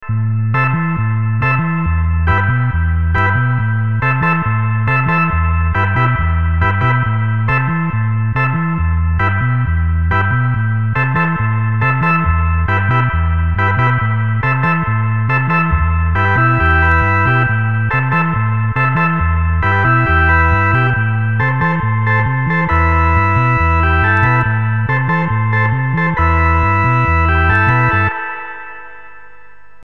Demo 8: at first the sound is the same as on demo 3, after that 2 2/3' - 2' is added (same sound as on demo 4)